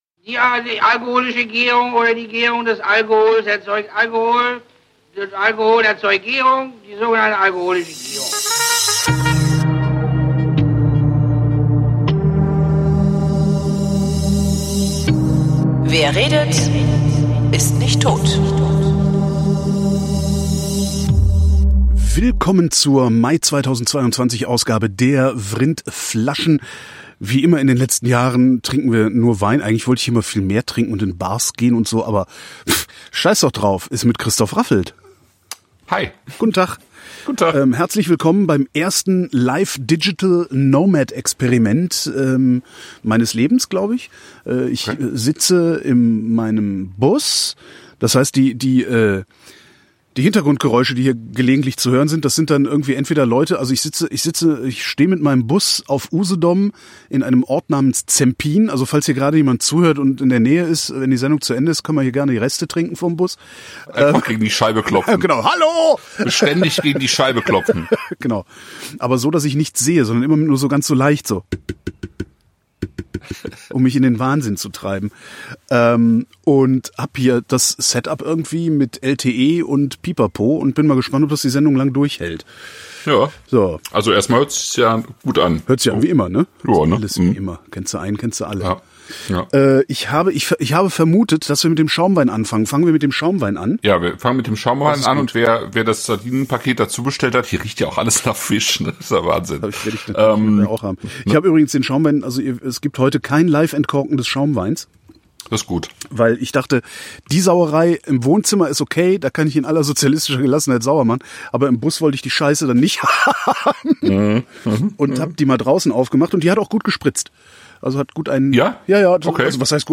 Live vom Campingplatz in Zempin trinken und essen wir Conceito Espumante 2018 (+ Jahrgangssardine 2020 von La Perle des Dieux), Contraste branco 2019 (+ Sardine mit Tapenade von La Belle Iloise), Contraste tinto 2019 (+ Sardine in pikanter Tomatensauce).